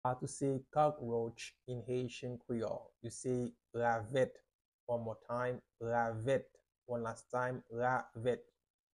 How to say “Cockroach” in Haitian Creole – “Ravèt” pronunciation by a native Haitian Teacher
“Ravèt” Pronunciation in Haitian Creole by a native Haitian can be heard in the audio here or in the video below: